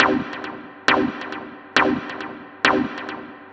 K-5 Stab Lo Filt.wav